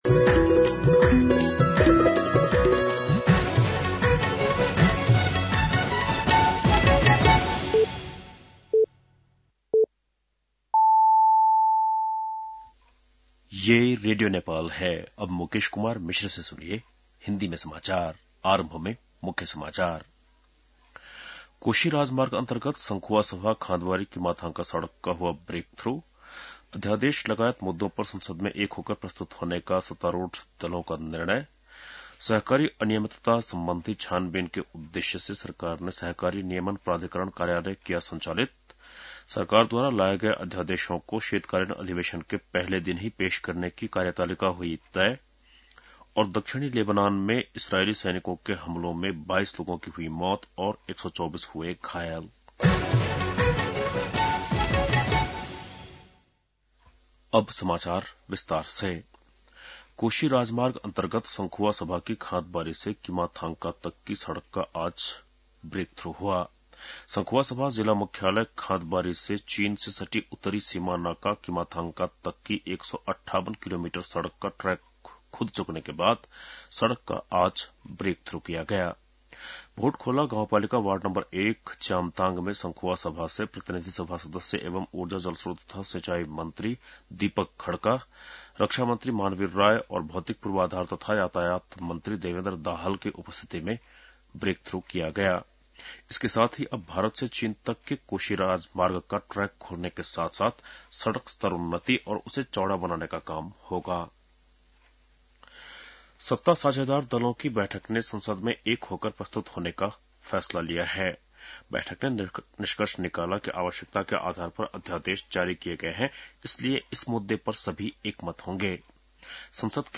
बेलुकी १० बजेको हिन्दी समाचार : १५ माघ , २०८१